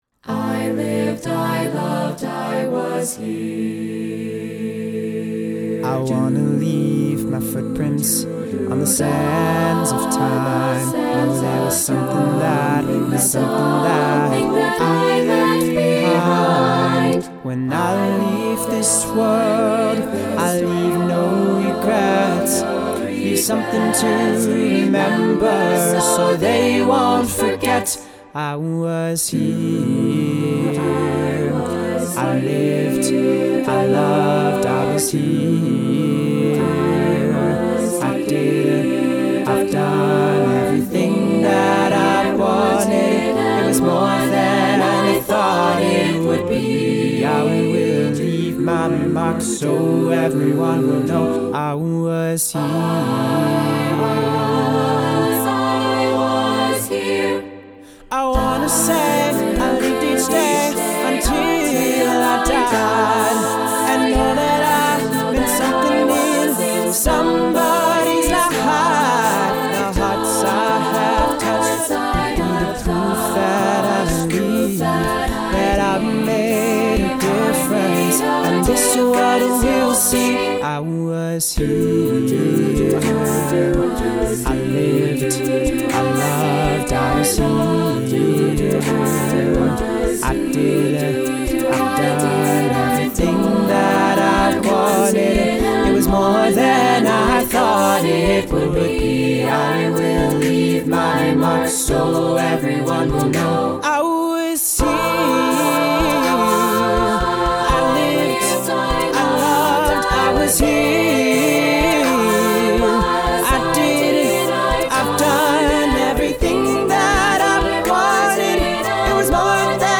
We operate on the individual-take model.